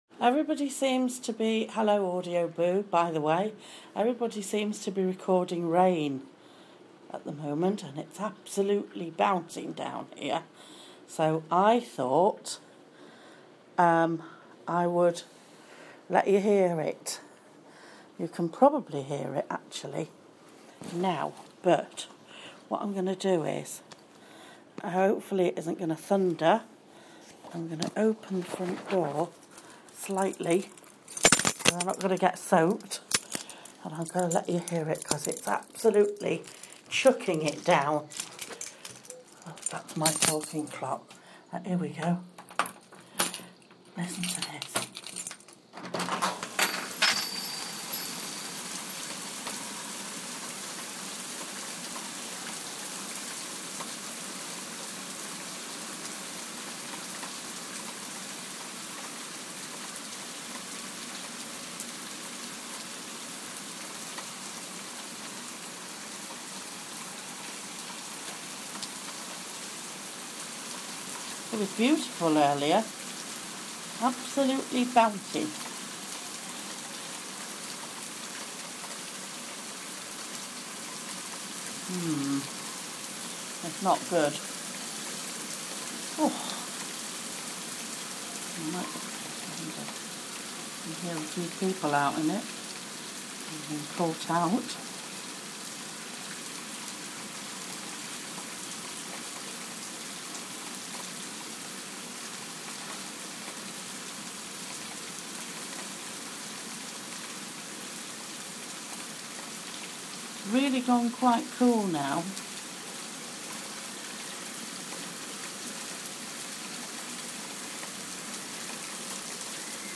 Some heavy rain and a couple of musical boxes